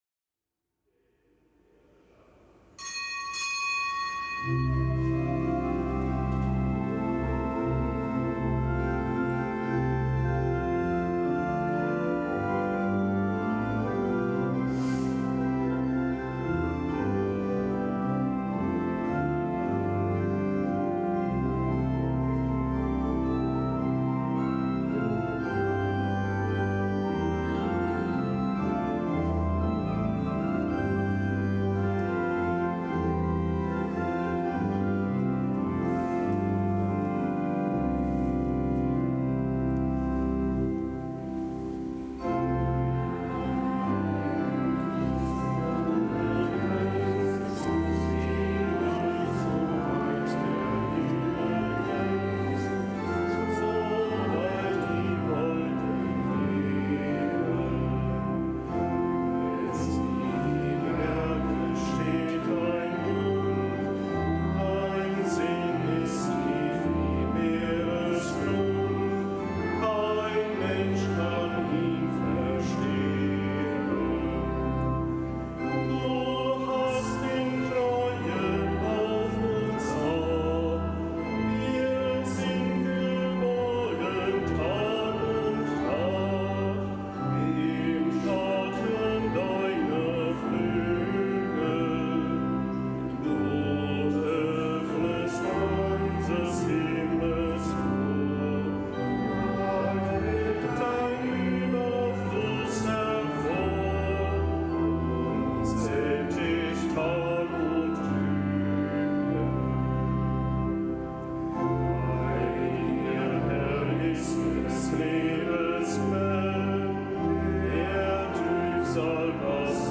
Kapitelsmesse am Donnerstag der fünfzehnten Woche im Jahreskreis
Kapitelsmesse aus dem Kölner Dom am Donnerstag der fünfzehnten Woche im Jahreskreis